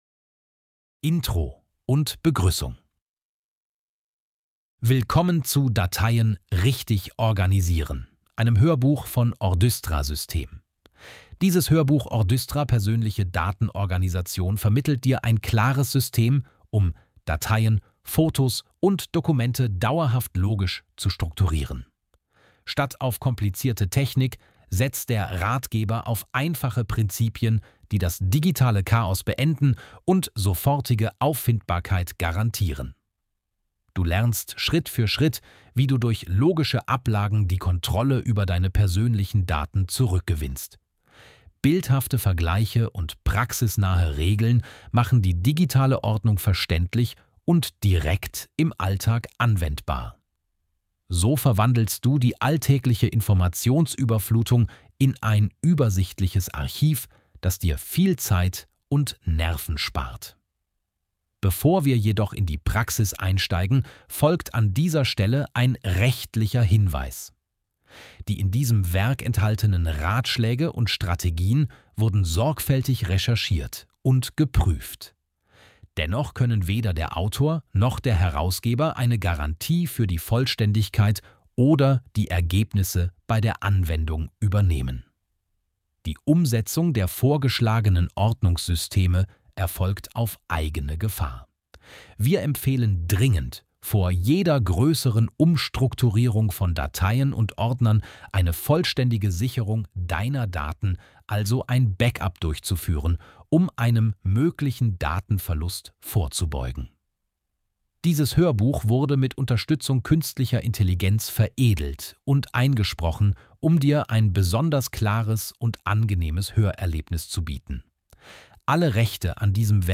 Dann ist dieses Hörbuch genau die Lösung, auf die du gewartet hast.
Ordystra-Band-2-dateien-richtig-organisieren-kapitel-00-intro-und-begruessung.mp3